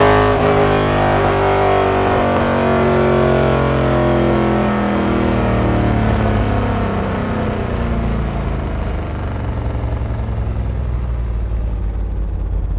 rr3-assets/files/.depot/audio/Vehicles/v8_08/v8_08_Decel.wav
v8_08_Decel.wav